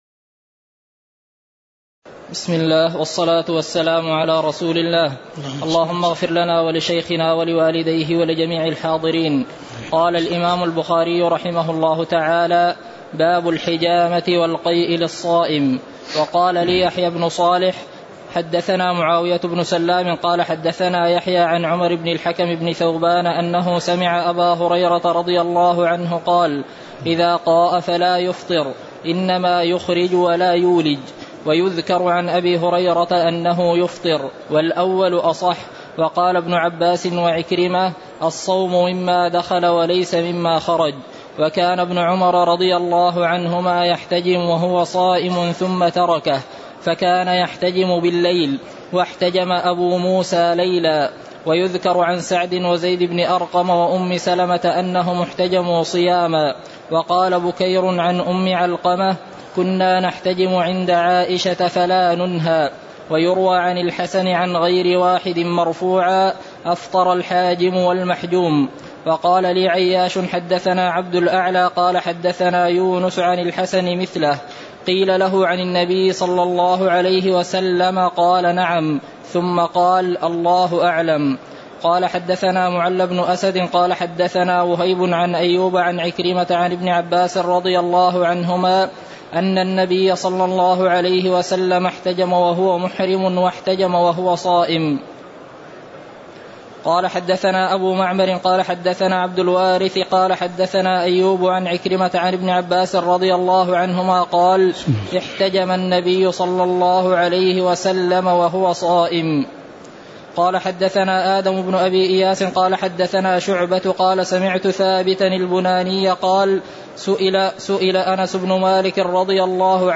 تاريخ النشر ١٠ رمضان ١٤٣٨ هـ المكان: المسجد النبوي الشيخ